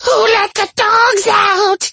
Kr4_fallen_ones_bone_flingers_taunt_2.mp3